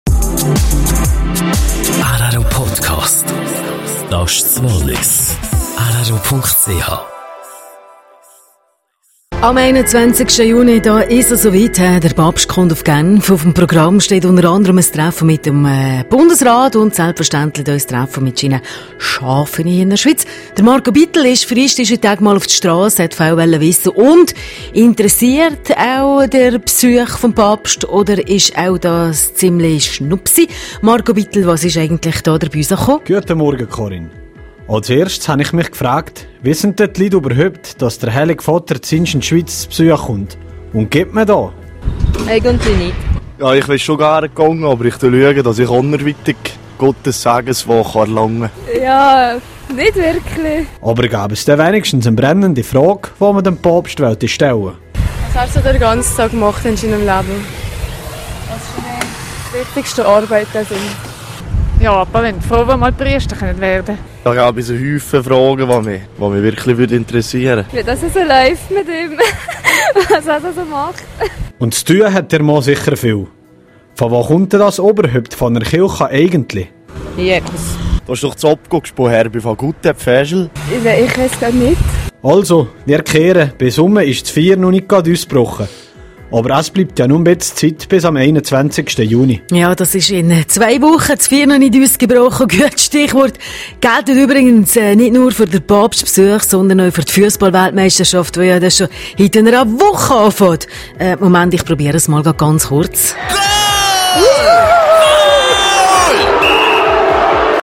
Papstbesuch in Genf: Strassenumfrage - Teil 3.